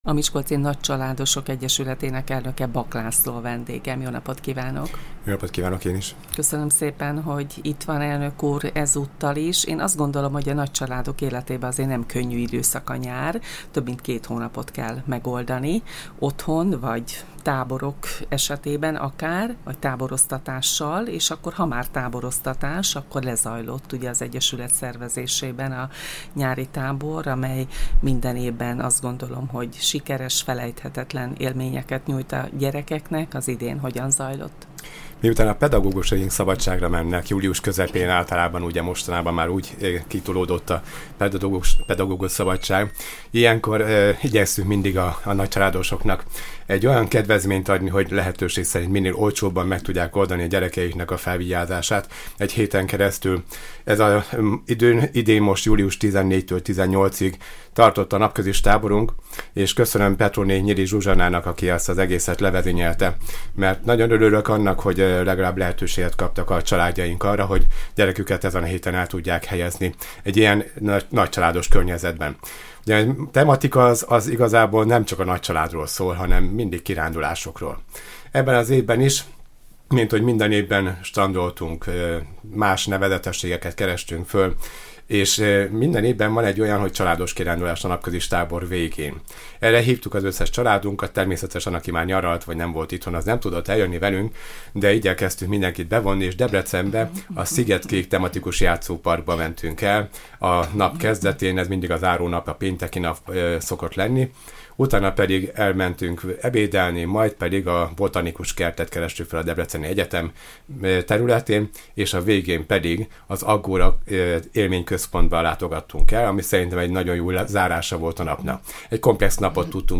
Az egyesület önkéntesei azonban már a szeptemberi tanévkezdésre is gondolnak, hiszen ezekben a napokban Miskolc több nagyáruházában veszi kezdetét a hagyományos tanszergyűjtésük. A beszélgetésből megtudhatják a pontos helyszíneket és időpontokat.